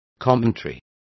Complete with pronunciation of the translation of commentaries.